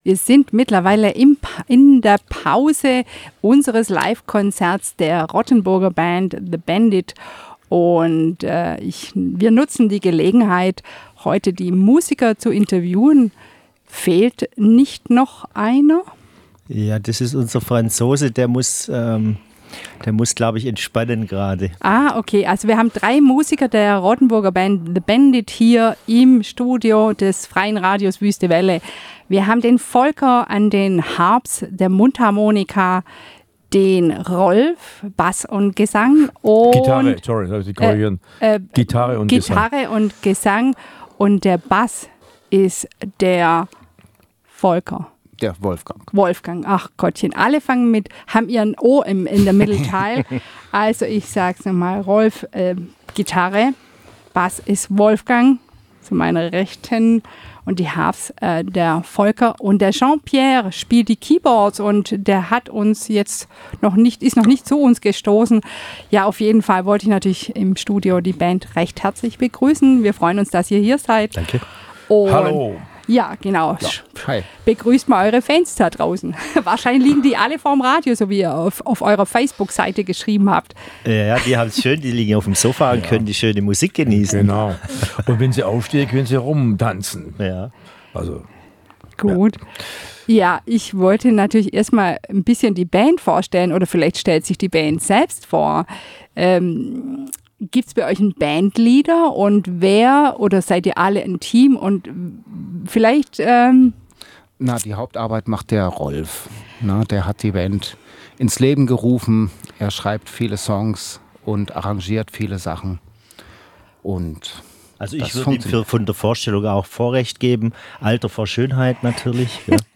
das ist ein dynamisch-bluesiges Quartett aus Rottenburg